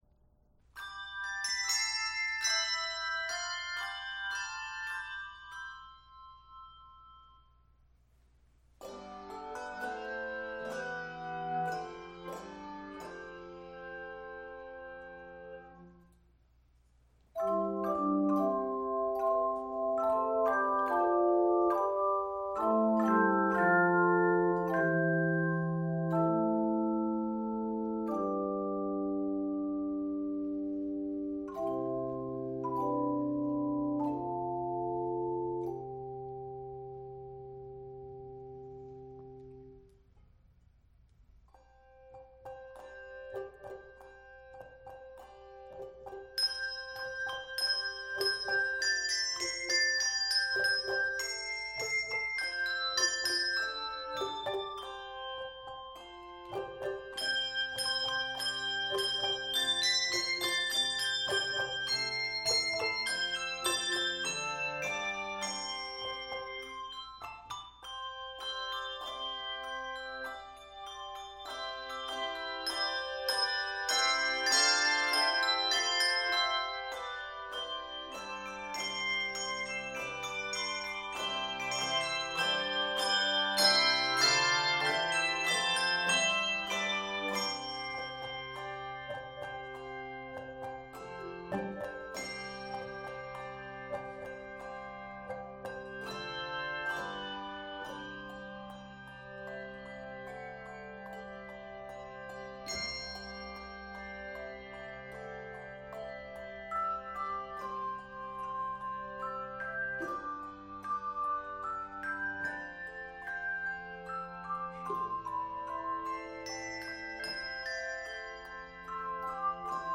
uplifting arrangement
makes use of martellato and malleted accompaniment patterns